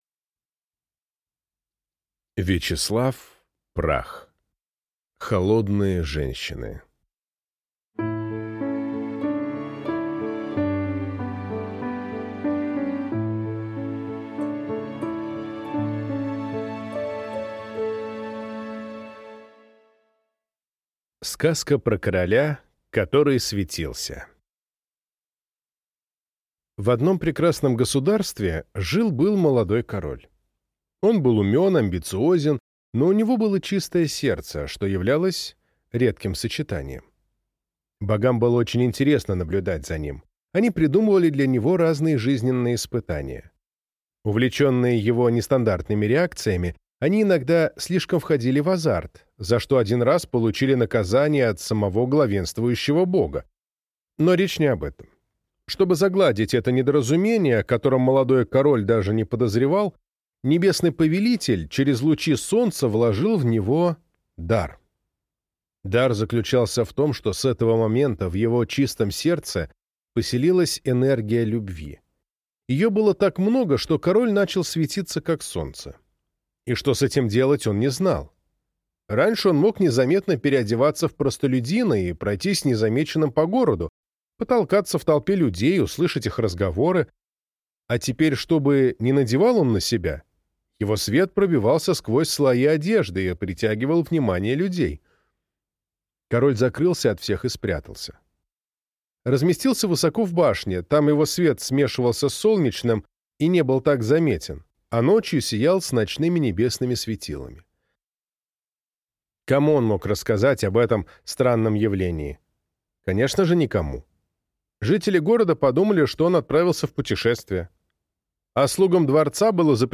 Аудиокнига Холодные женщины | Библиотека аудиокниг